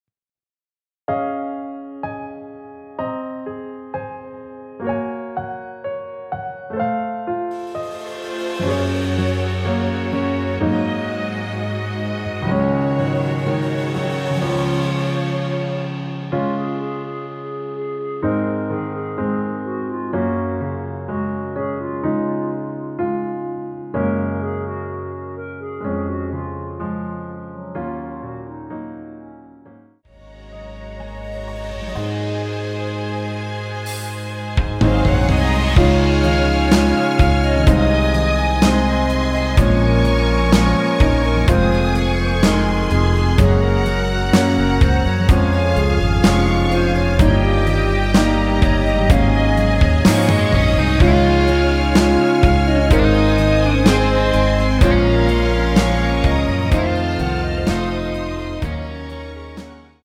원키 멜로디 포함된 MR입니다.(미리듣기 확인)
Db
앞부분30초, 뒷부분30초씩 편집해서 올려 드리고 있습니다.